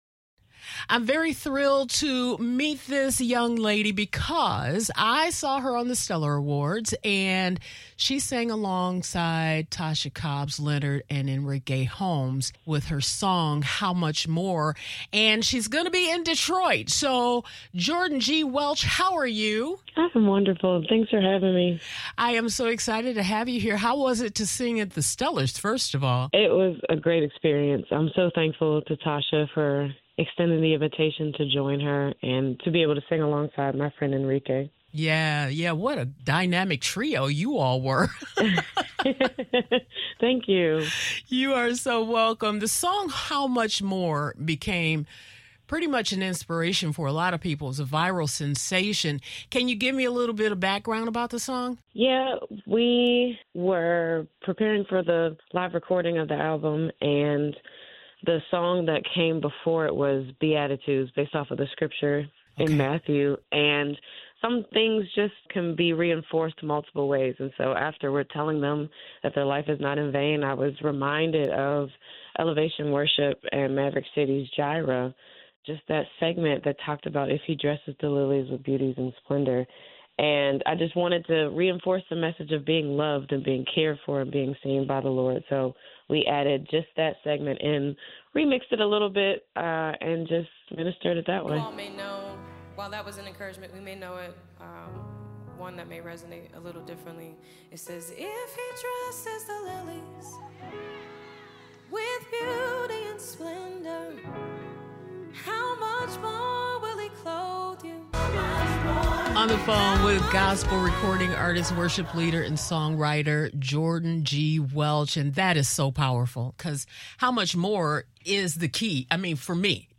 🎧 Listen to our full conversation below for more about her journey, advice for new artists, and how she’s blending gospel roots with digital ministry to reach thousands around the world.